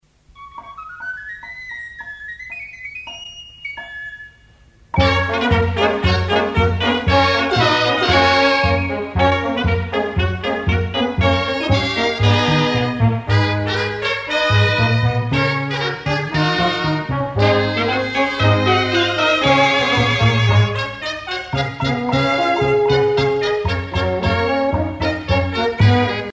danse : sardane
Pièce musicale éditée